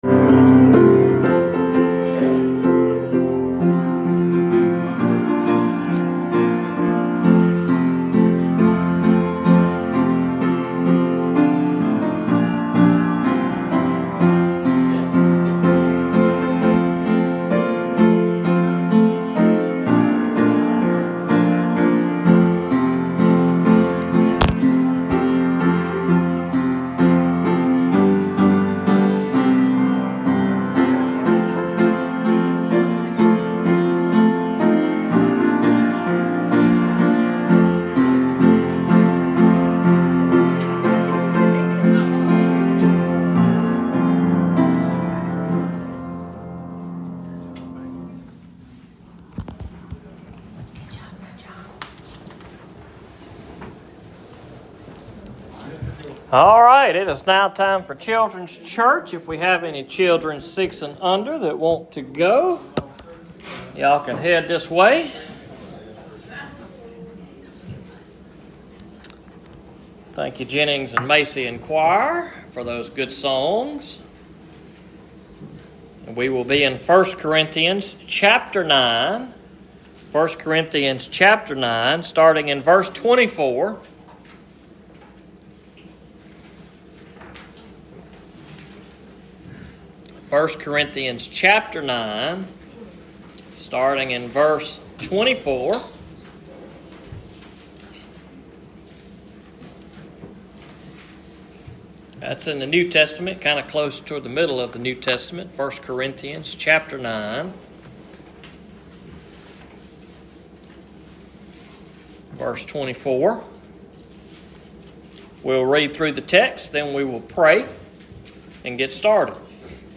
Sermons preached at Enterprise Baptist Church in Liberty MS